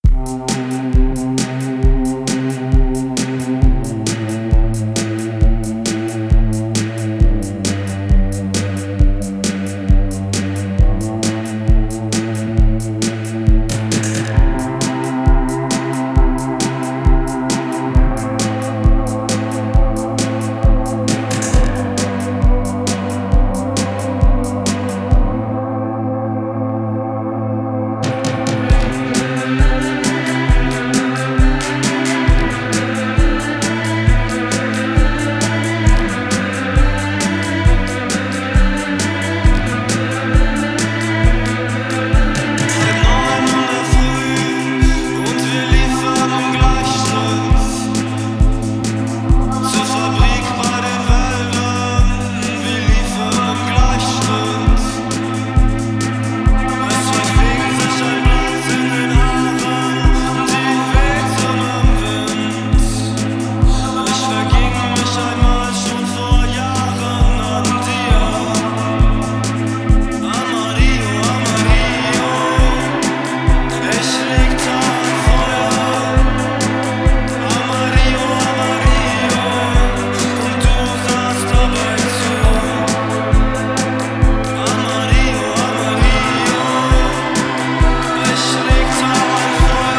Sinister cold wave
Electro Wave